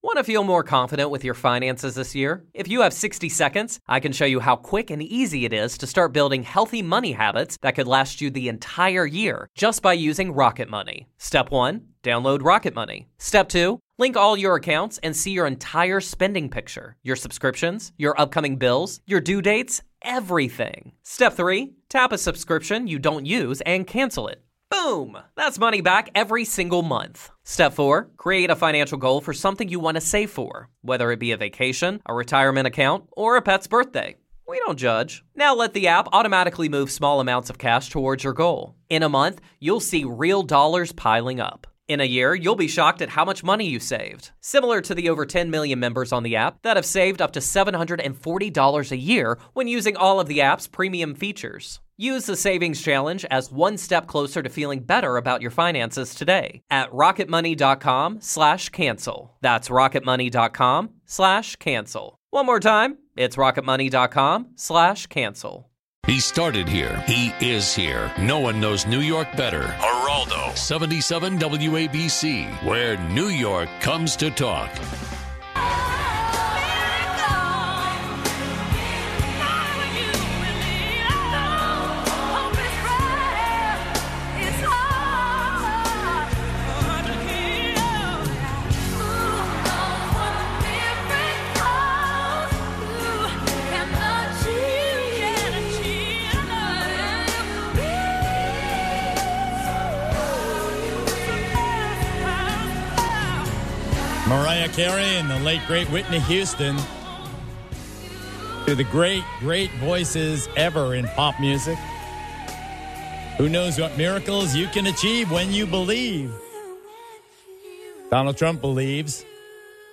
Geraldo has an exclusive interview with Donald Trump! Geraldo & Trump hit many big topics, plus a look around the rest of the news.